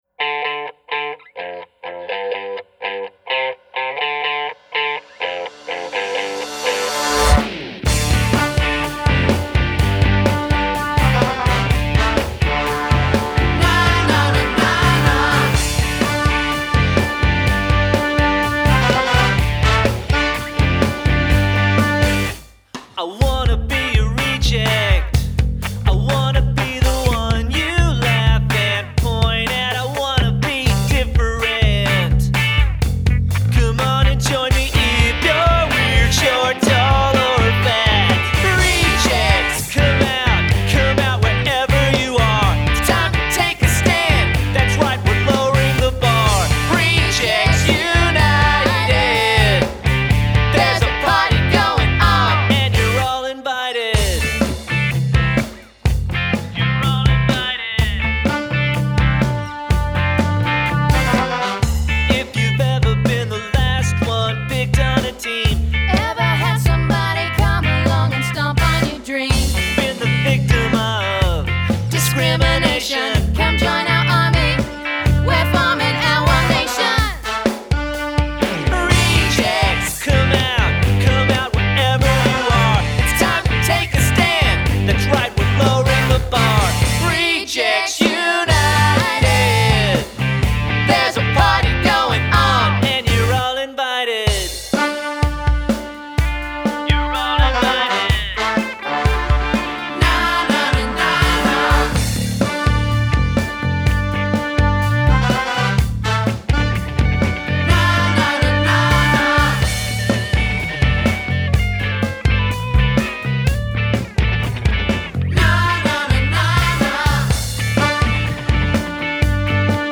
Genre: Tween Rock/Ska